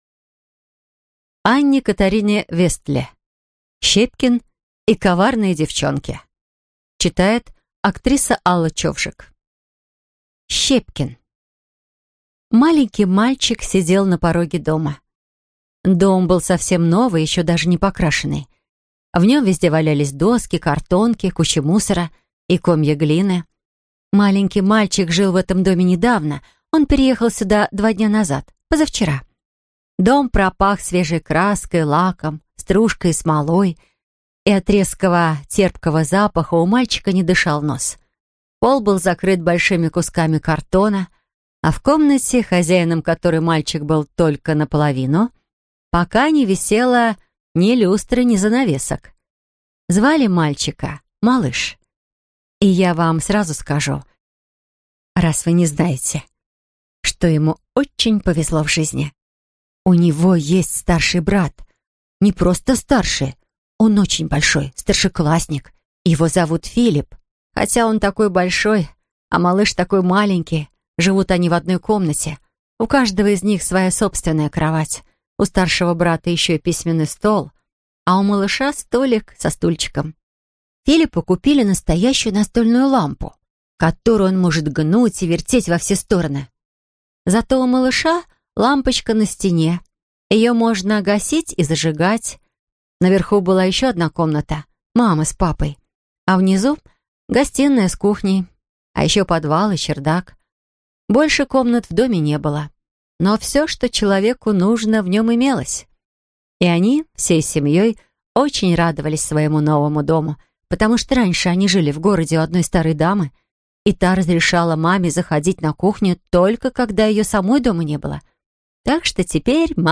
Аудиокнига Щепкин и коварные девчонки | Библиотека аудиокниг